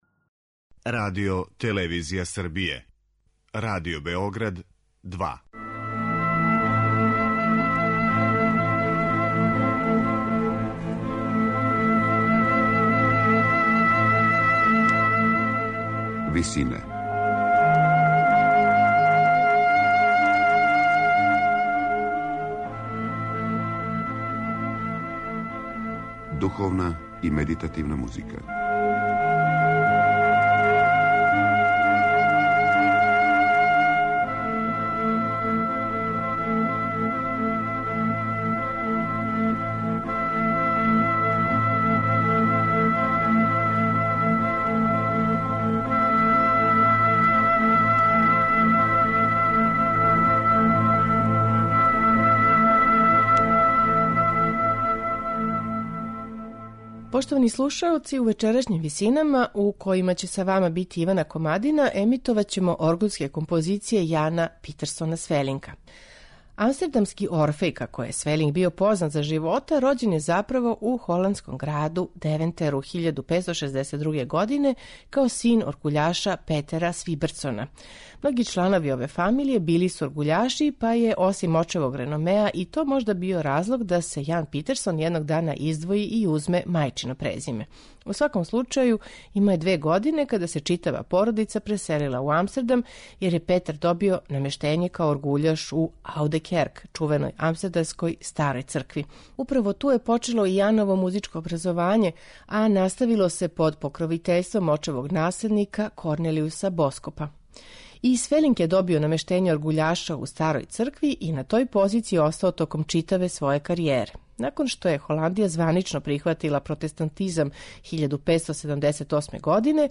Није познато да ли је разлог томе његов велики опус вокалних дела или виртуозно умеће оргуљаша, којим је опчињавао слушаоце у Старој цркви у Амстердаму.
оргуљске токате, обраде псалама, фантазије и корале